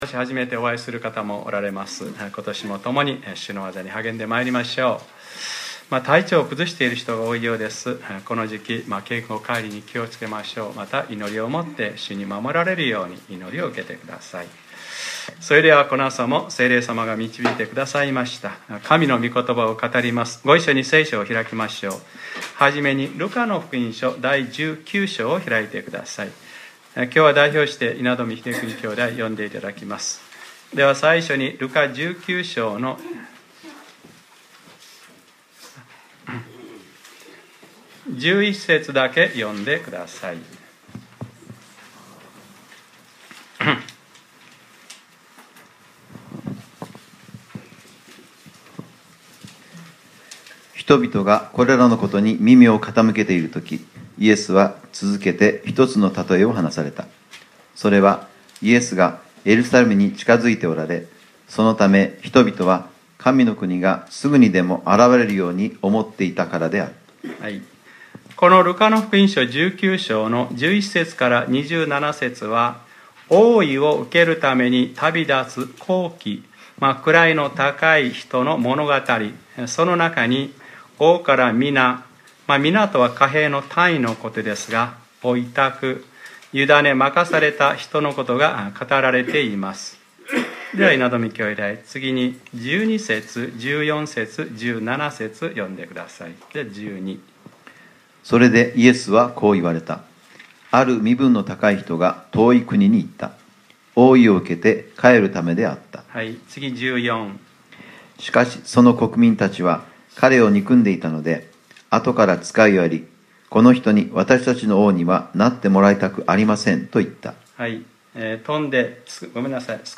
2015年01月11日）礼拝説教 『ご主人さま。あなたの１ミナで 』